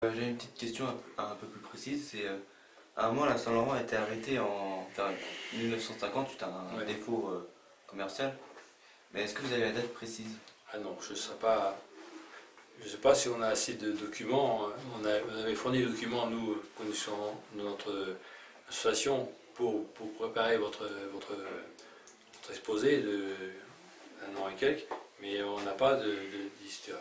Interview au lycée Saint-Clair
Catégorie Témoignage